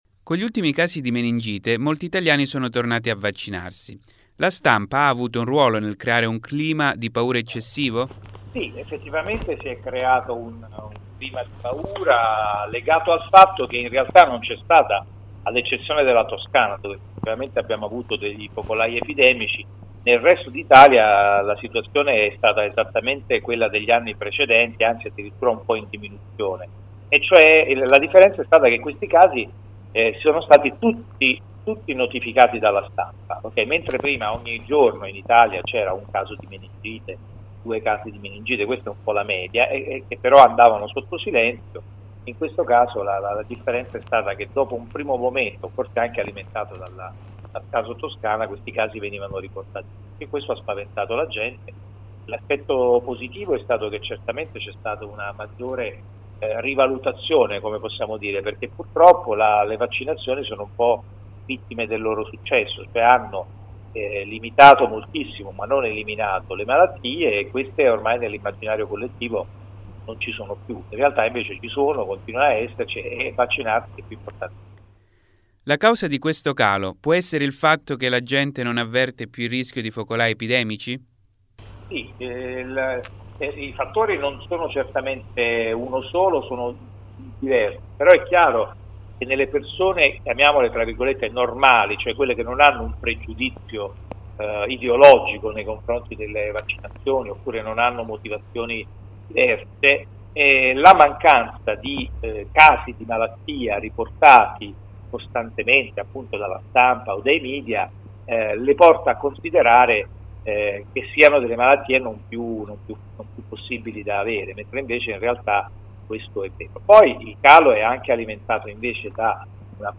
Lumsanews intervista Walter Ricciardi
L’audio dell’intervista a Lumsa News al presidente Walter Ricciardi